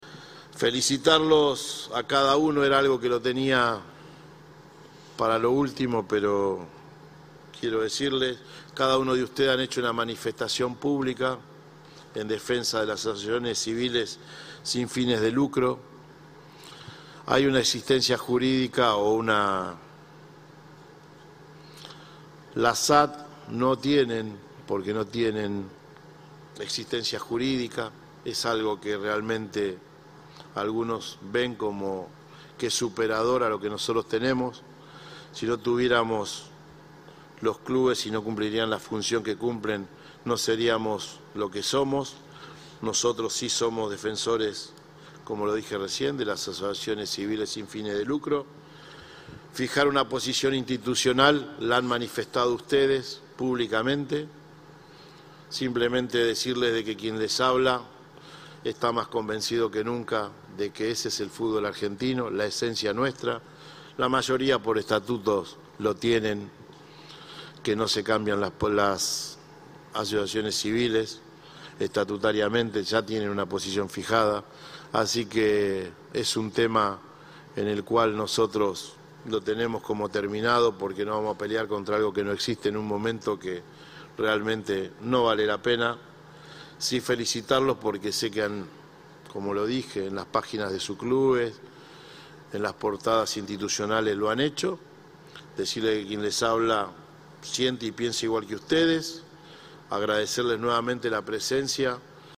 "Chiqui" Tapia ratificó su rechazo a las SAD en la Asamblea de AFA.